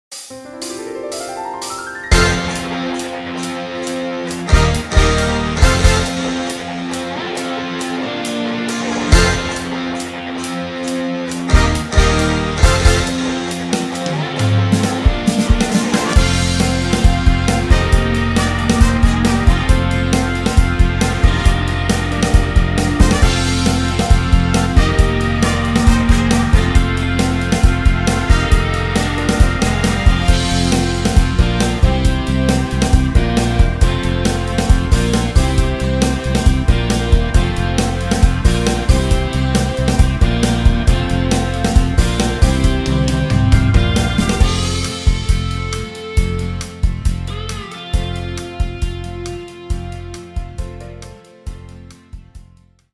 live Amiens 2011